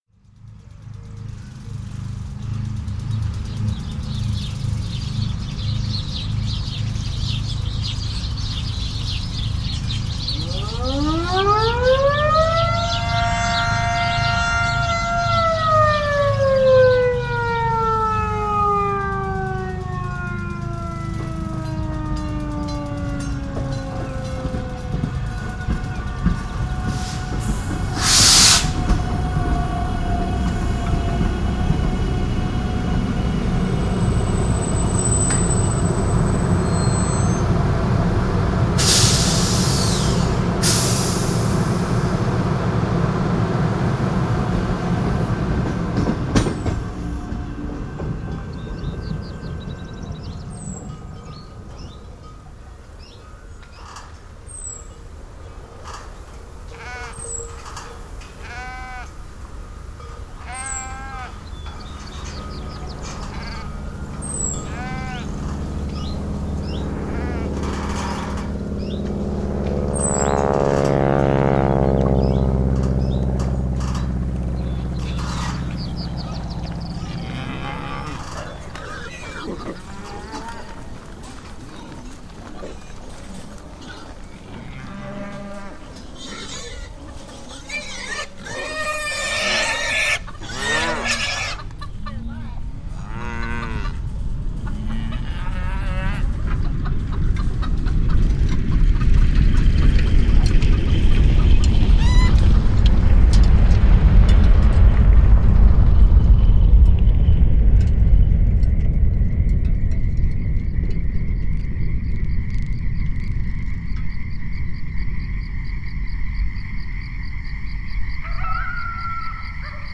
城镇与乡村音效库包含丰富的环境音效，融合了自然之声与人造噪音
更细致、更易获取的声音，例如篝火声、农场动物的声音，甚至是令人印象深刻的苍蝇叮咬路边动物尸体的声音，都将为您的音效库增添强大的补充
超过 200 个生动的城镇和乡村日夜音效文件，让您能够灵活地将充满动物的乡村生活与人造的城市声音完美融合
我们录制了超过 8 GB 的城镇和乡村清晨、白天和夜晚的典型环境音
涵盖野生动物、人类活动、工作声、交通声、火声、水声、林地声、风车声等等。